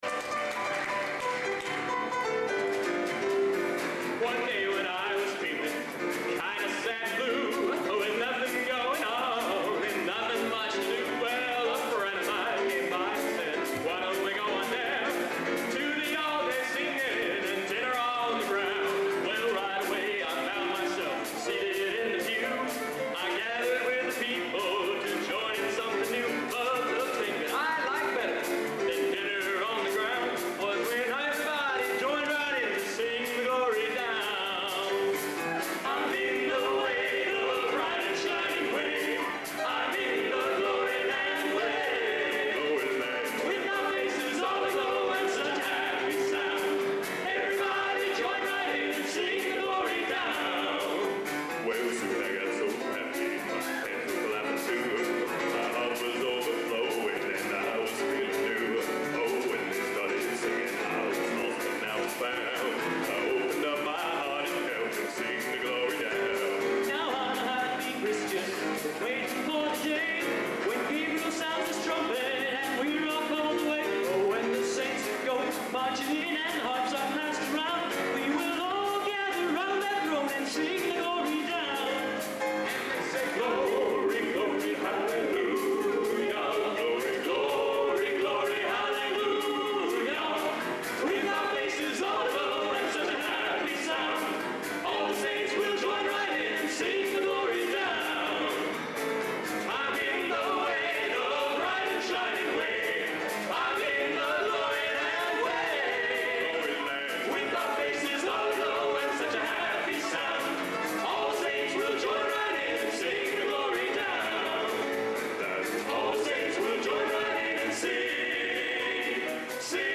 Location: Purdue Memorial Union, West Lafayette, Indiana
Genre: Gospel | Type: Featuring Hall of Famer |Specialty